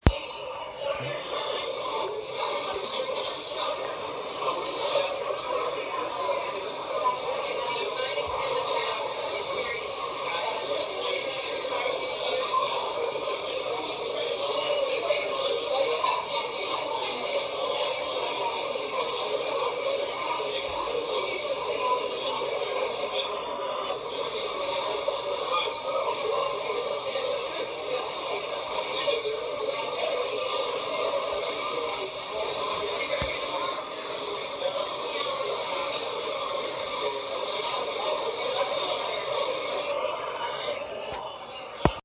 Field Recording #3
Location: Bits and Bytes
Sounds heard: General conversation, Plastic salad container opening, Chair sliding, Voice from t.v.